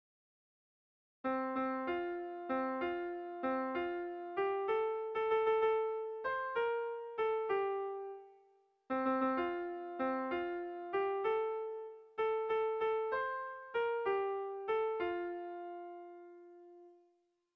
Irrizkoa
AB1AB2